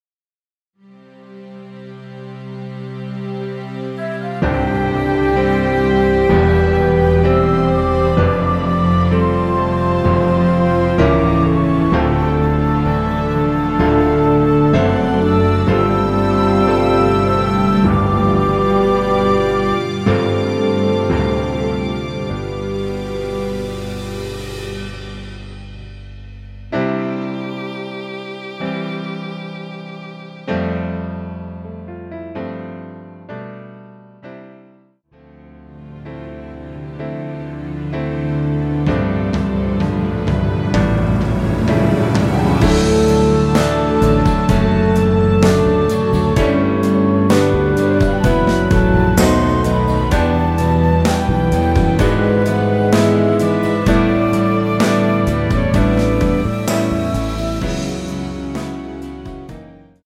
원곡이 너무 길어 축가나 행사에 사용하실수 있게 3분 53초로 편곡 하였습니다.
미리듣기는 처음부터 35초 까지와 중간 ~사랑해줘서 그댄 아름다운 신부 ~부분 입니다.
<전주 26초 정도> 원곡은 약 50초
곡명 옆 (-1)은 반음 내림, (+1)은 반음 올림 입니다.